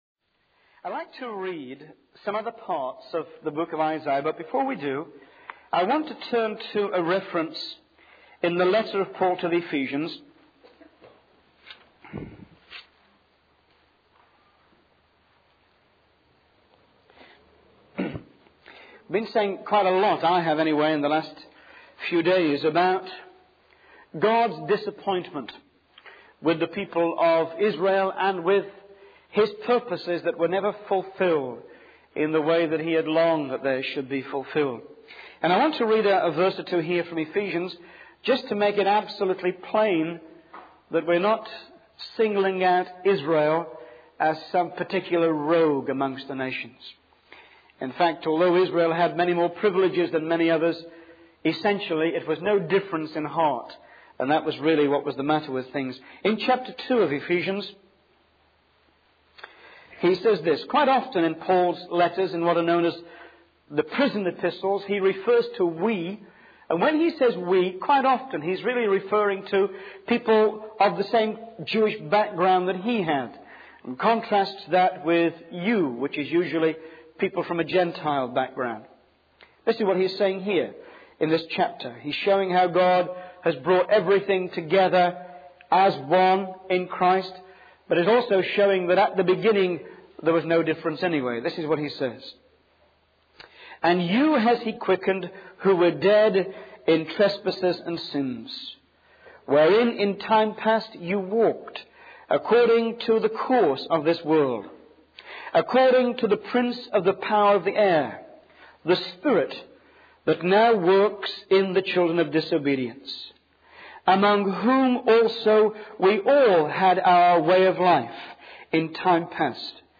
In this sermon, the speaker discusses two stories written by C.S. Lewis, 'Out of the Silent Planet' and 'Perelandra,' which depict the creation of new worlds and the threat of evil.